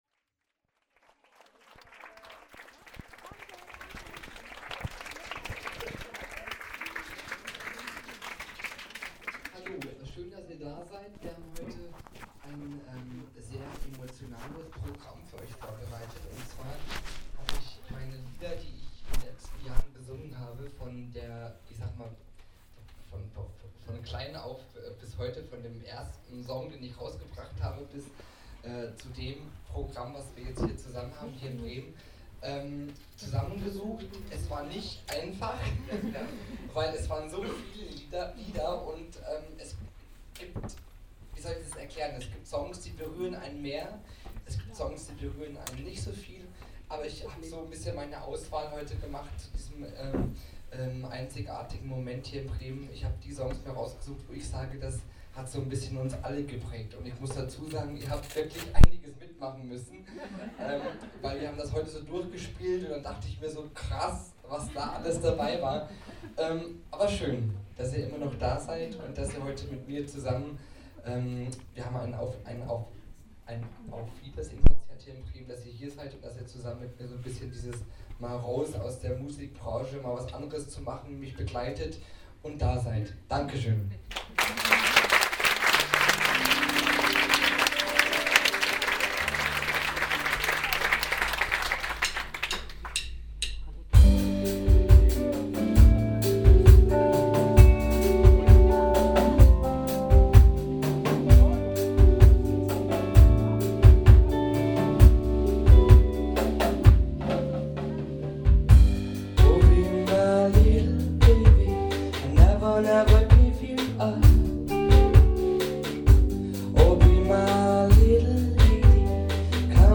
Konzertbericht
Es ging dann weiter mit Country